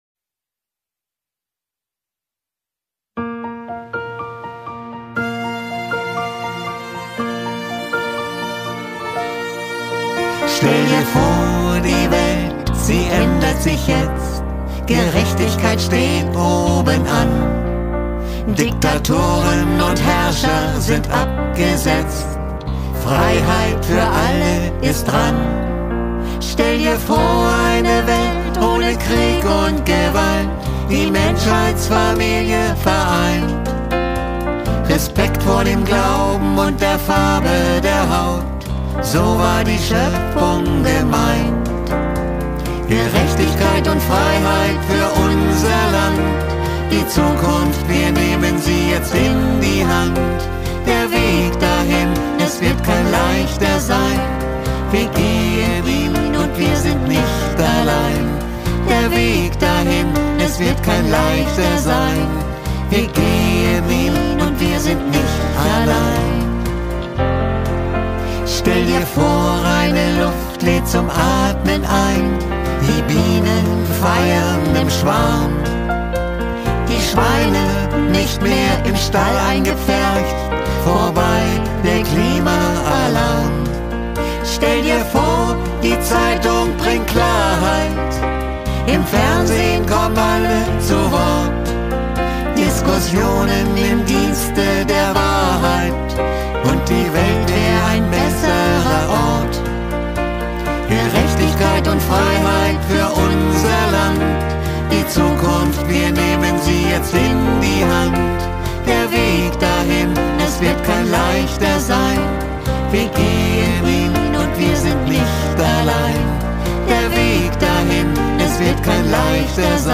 Duo "Ziemlich Anders" Stell Dir vor, die Welt die ändert sich jetzt.....